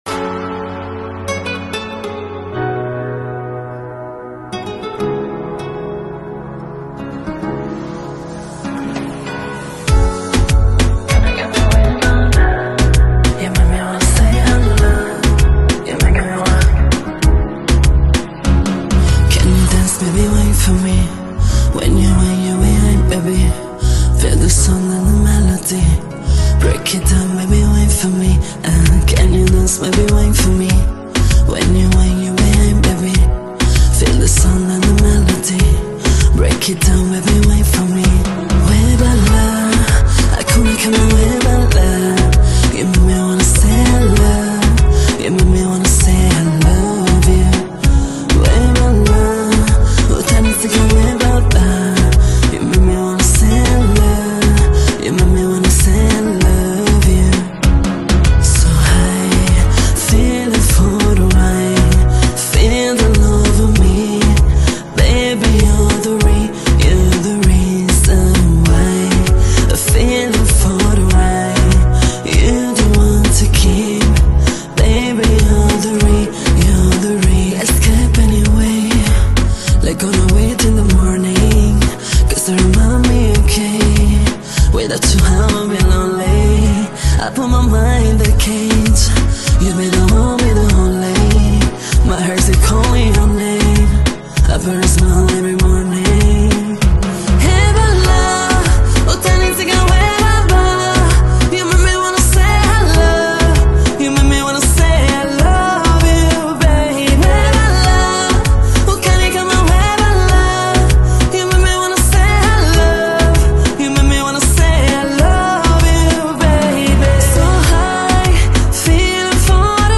delivers smooth and emotional vocals